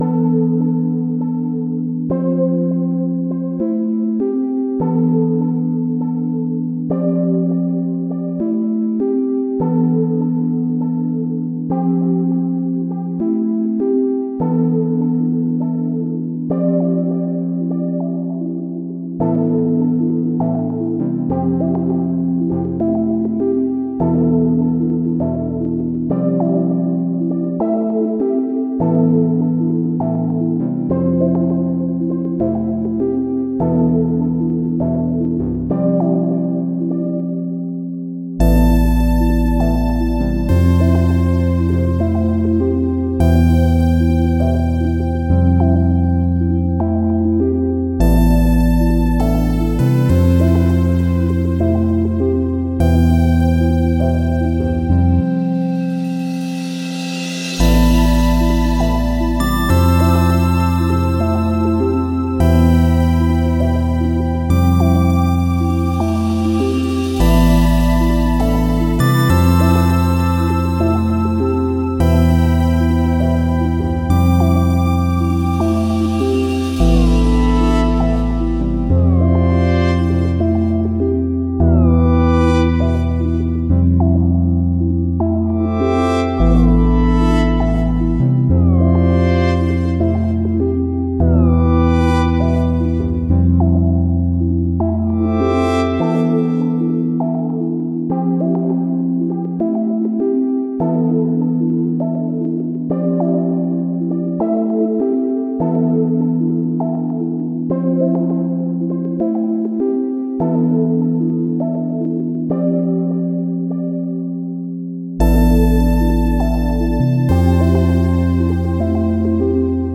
Longing synth patterns build an uneasy ambient tension.